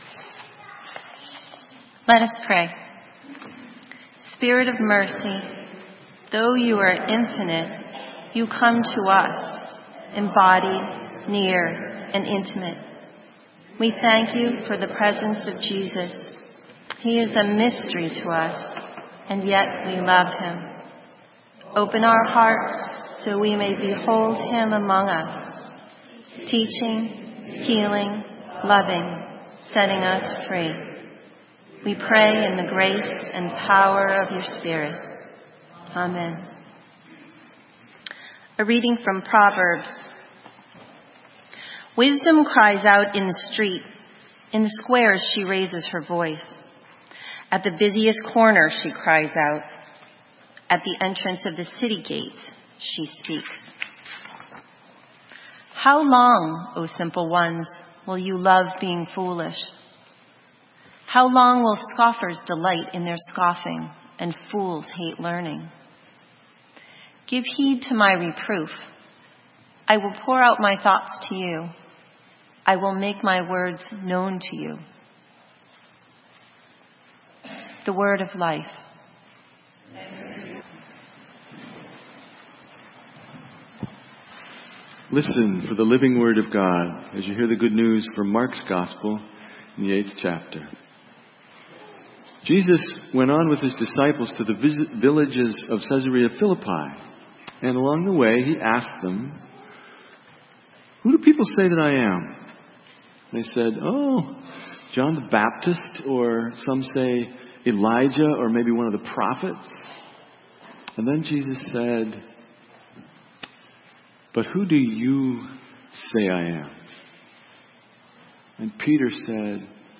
Sermon:Who do you say I am?